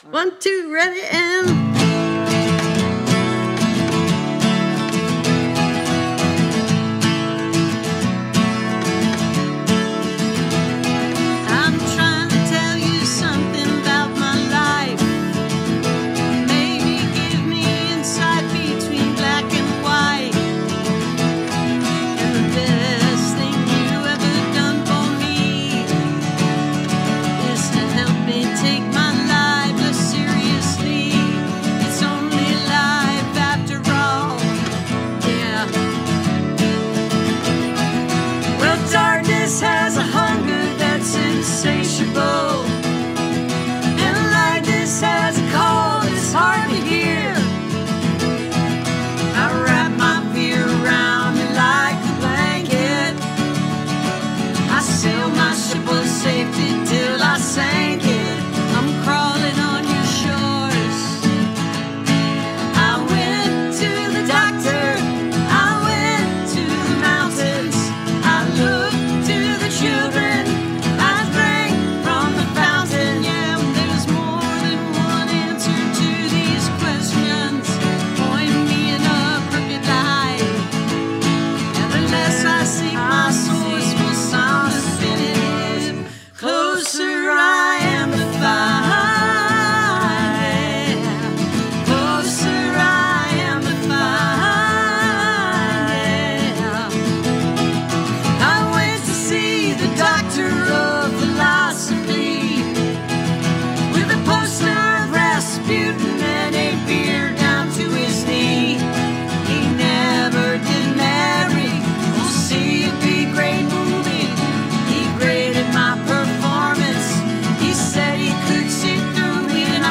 (audio captured from webcast)